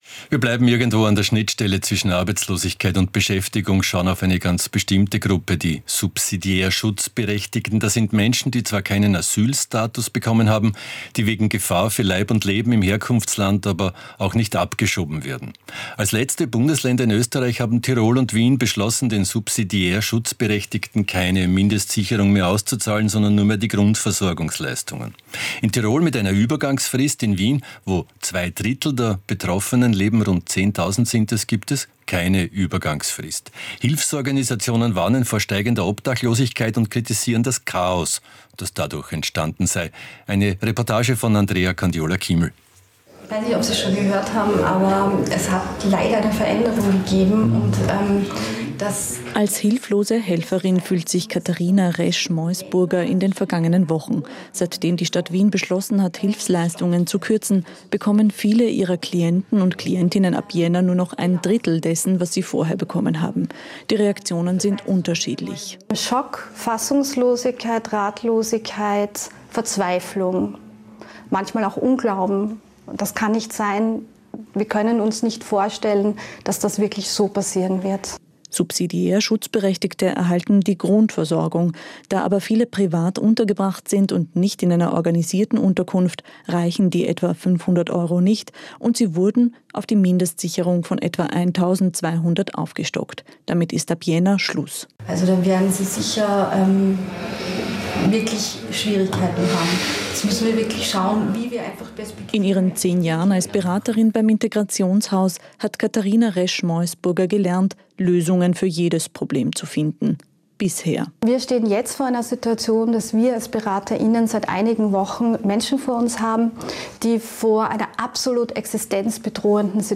Ö1 Interview mit den NACHBARINNEN zu Sozialkürzungen
Newsbeitrag vom